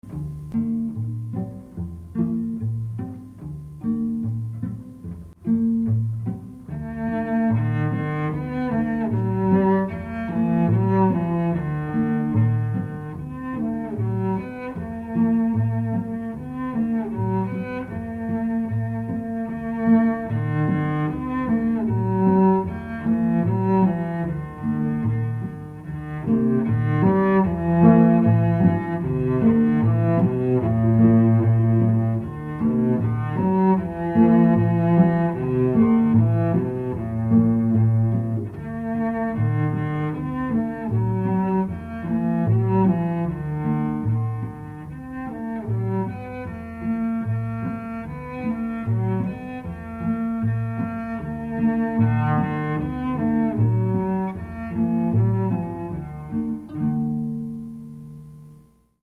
Pièces pour 4 violoncelles